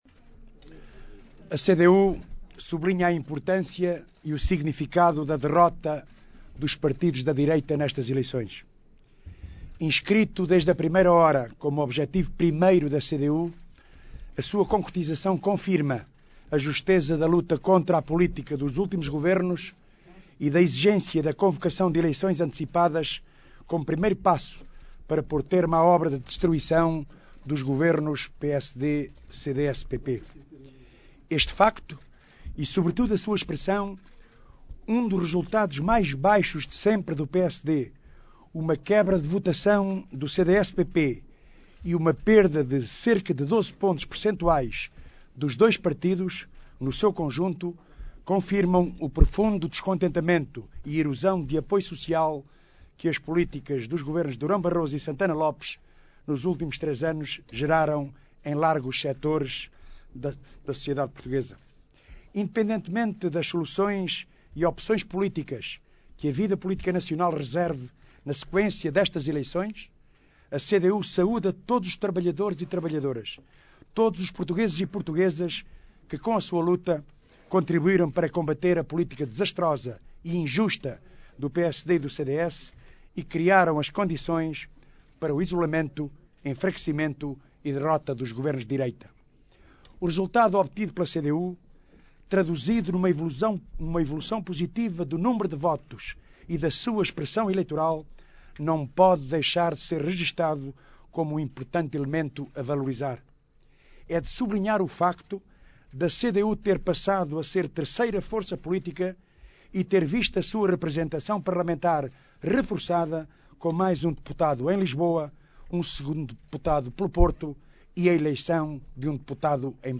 Intervenção de Jerónimo de Sousa, Secretário-geral do PCP